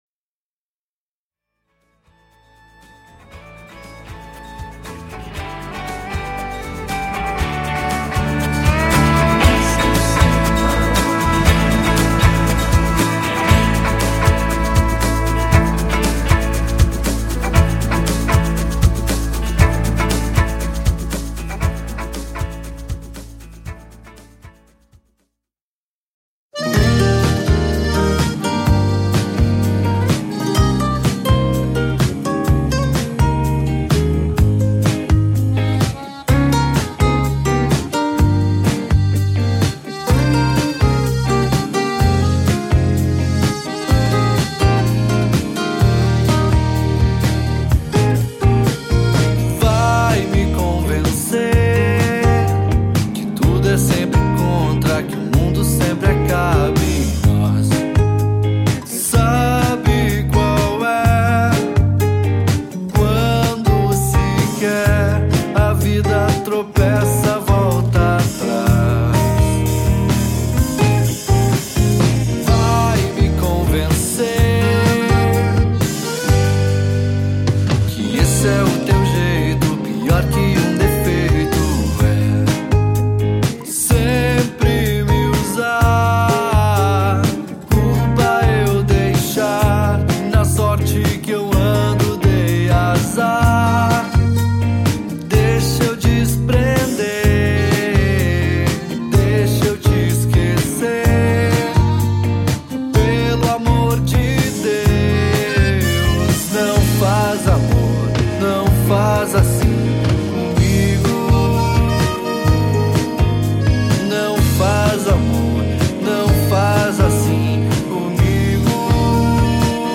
voz/guitarra
baixo
bateria
piano